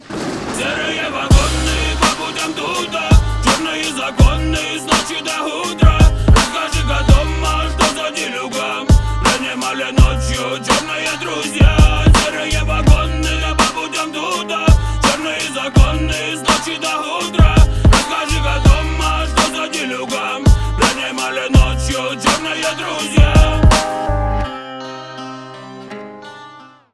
Рэп и Хип Хоп
громкие # грустные